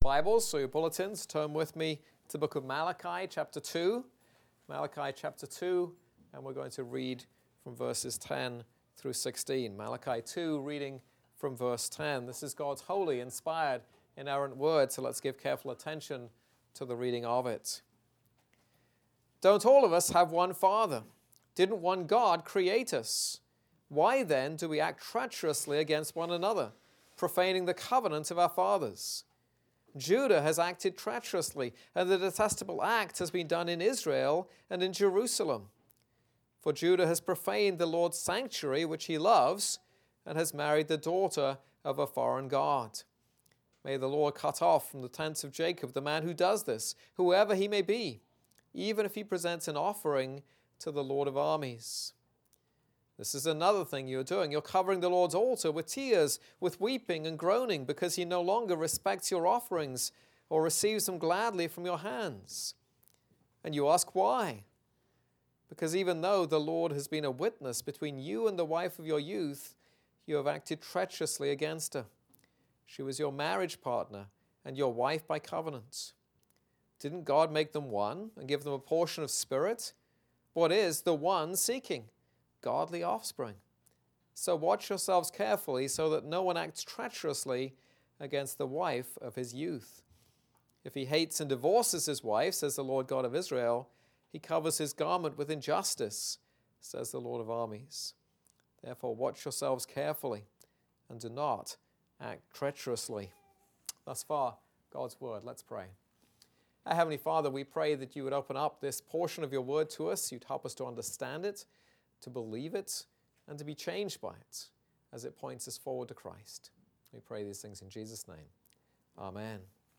This is a sermon on Malachi 2:10-16.